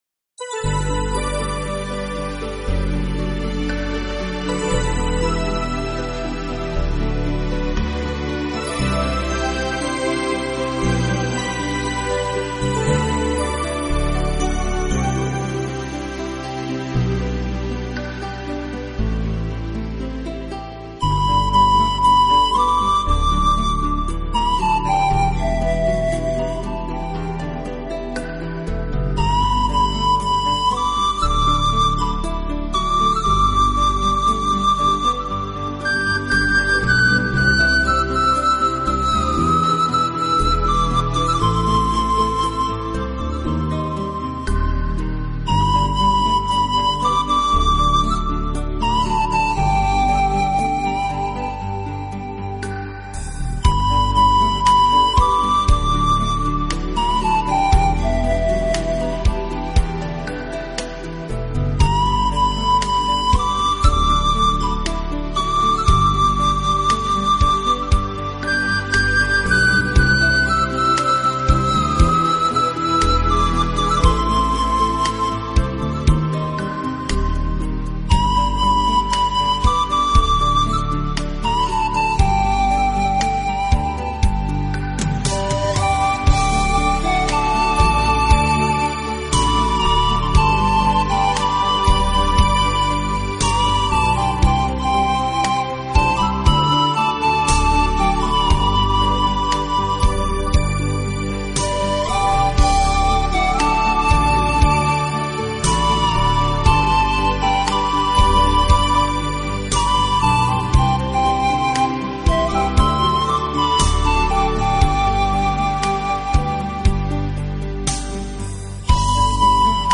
以如梦似幻的清新自然音乐，著称乐坛。
收藏大自然的元音，用其独特超广角的音场、空灵缥缈的编曲构成最唯美的大
那轻灵脱俗的旋律将引导着您远离尘世的喧嚣，亲近浩瀚的大自然。